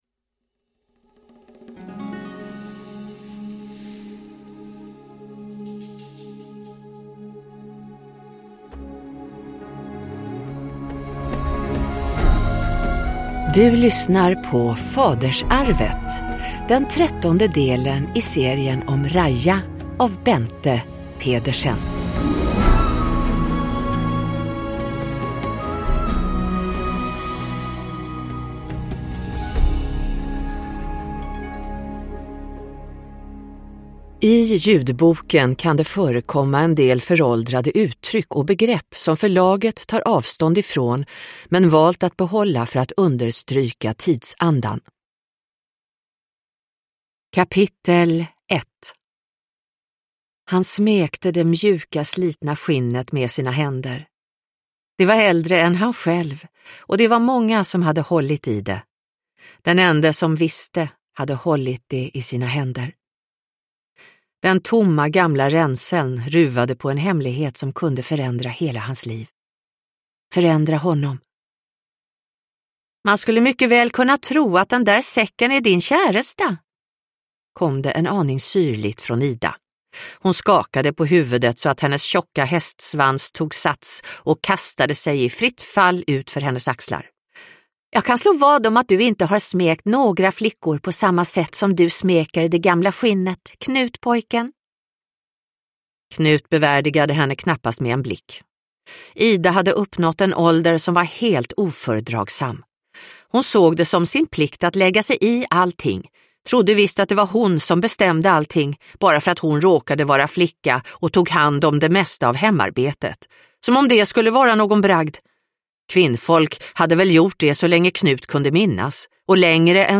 Fadersarvet – Ljudbok – Laddas ner